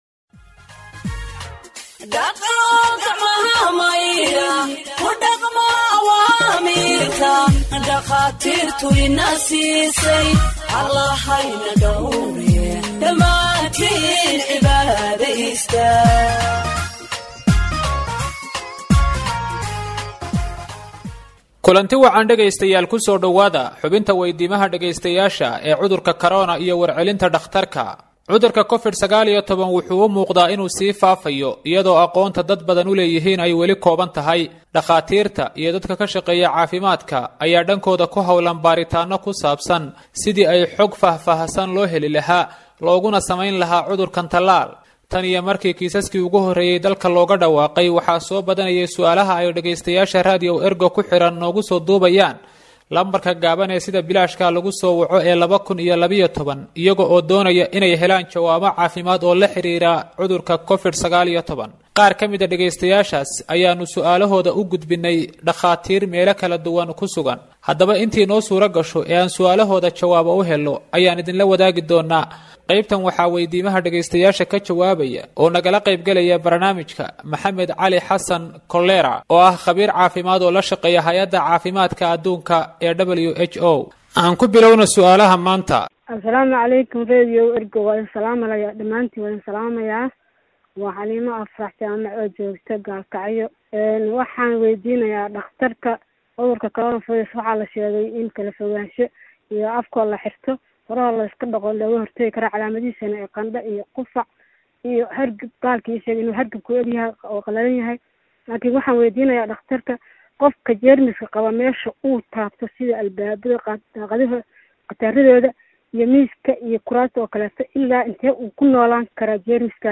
Health expert answers listeners’ questions on COVID 19 (19)